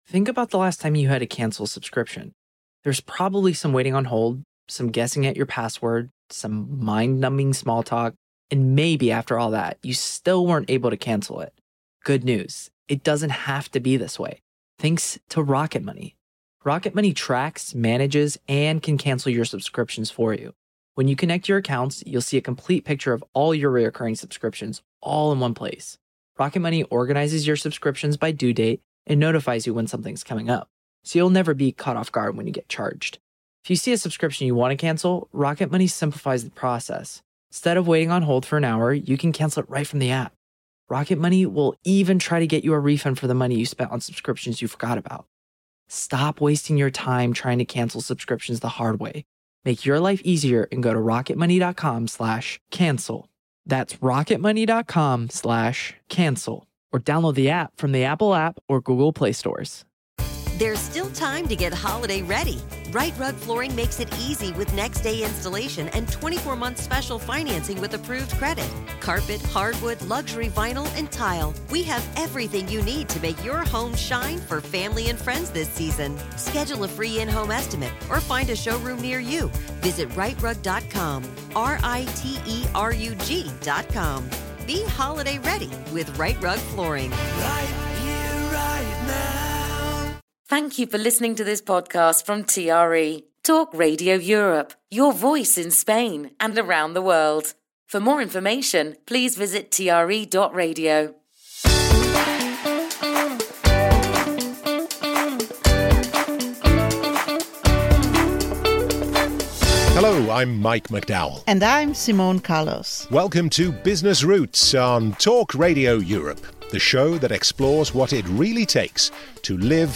Tune into Business Routes where you'll hear inspirational stories from entrepreneurs who set up and run their own enterprises here in Spain.